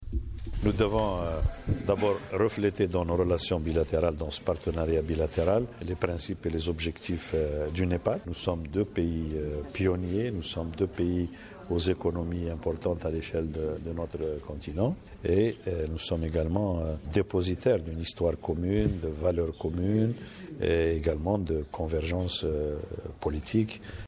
Le ministre algérien des Affaires étrangères, Ramtane Lamamra, au micro